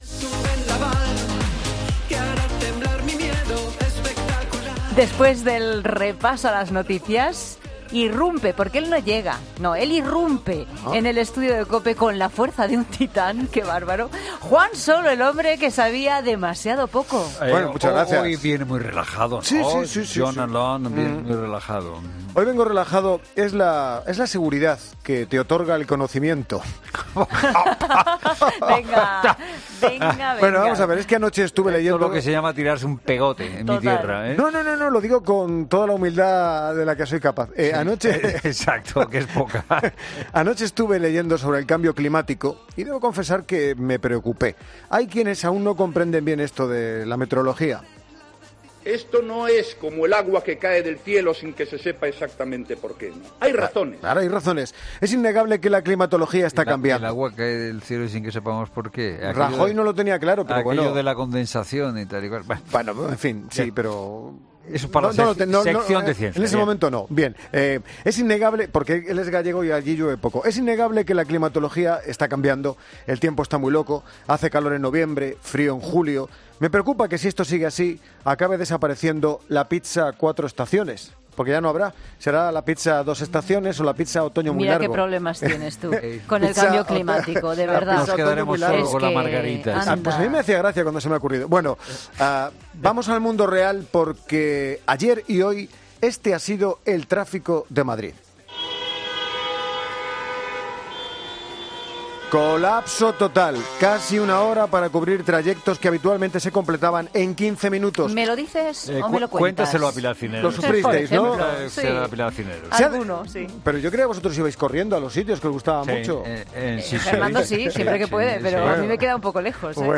es un magazine de tarde que se emite en COPE